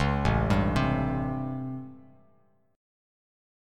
A7#9 chord